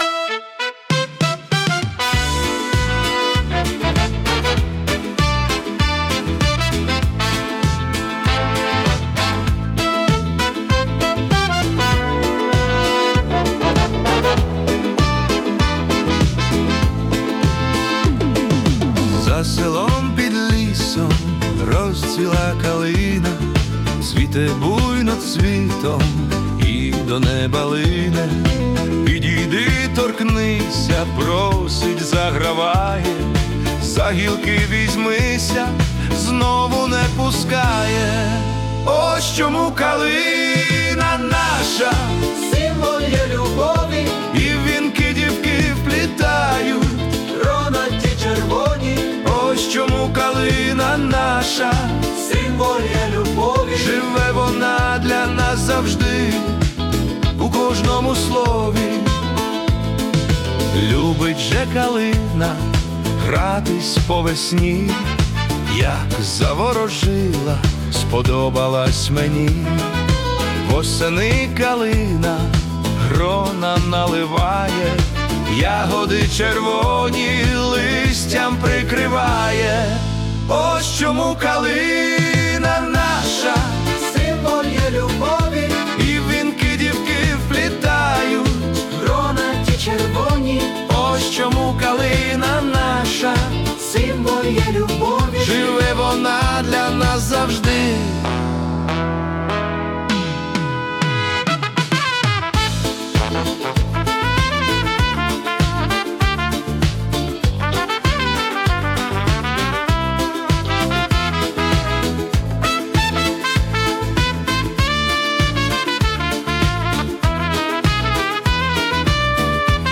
Жанр: ВІА 80-х.
Ностальгійна музика у стилі ВІА 80-х 📻